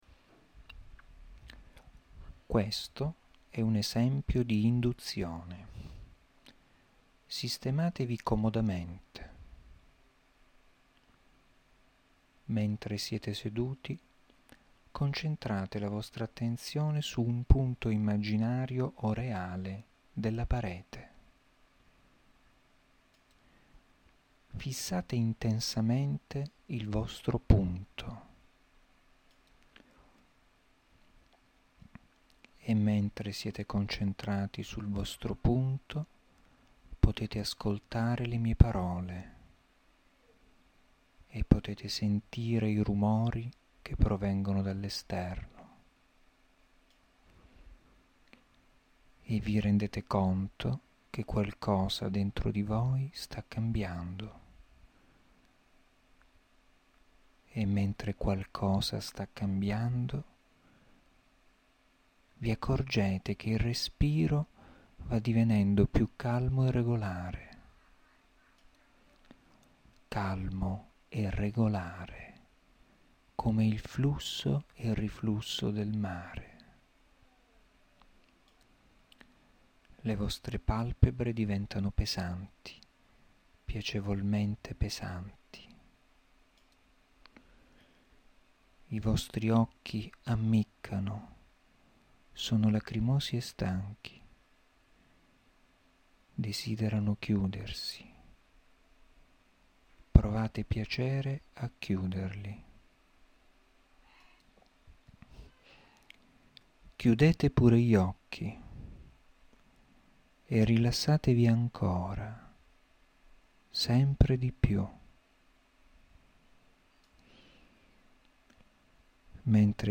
Esercizi di rilassamento
in questa area troverà alcuni esercizi di rilassamento da me registrati, comprendenti una induzione iniziale, una suggestione ed una visualizzazione (fantasia guidata).
• Primo esempio di induzione, Relax.